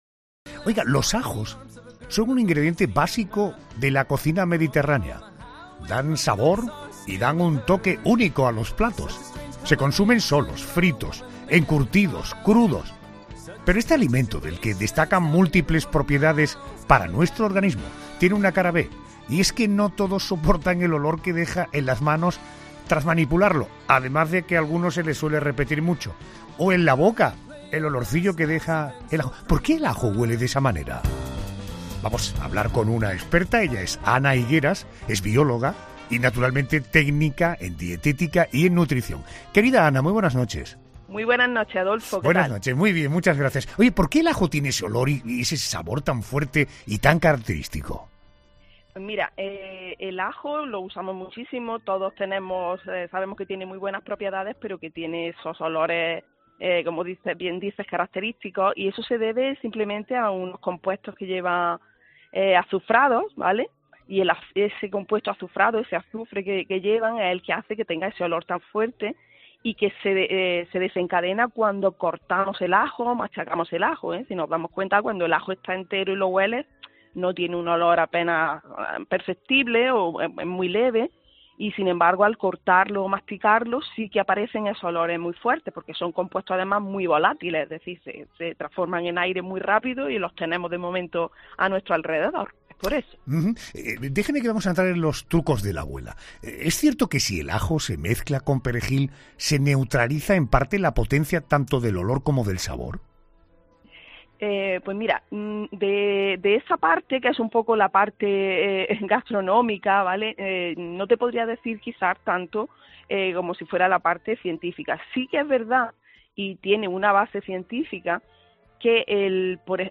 conversación con la bióloga